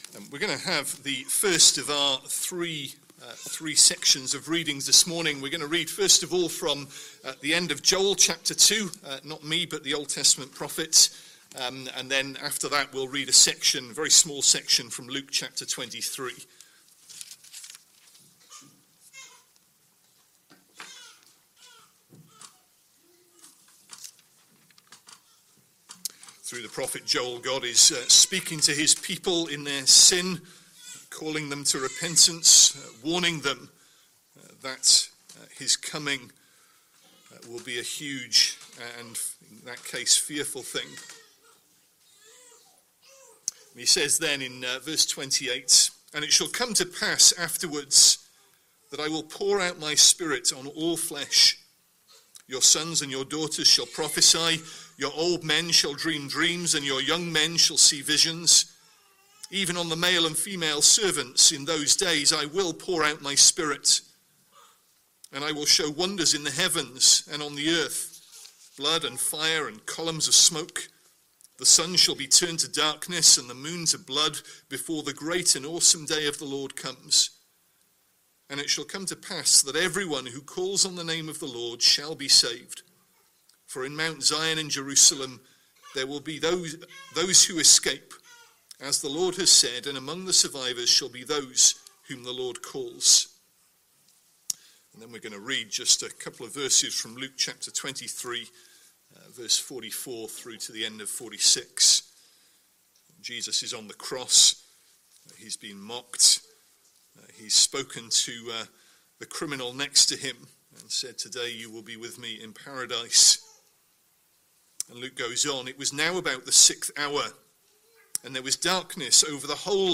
Sunday Evening Service Speaker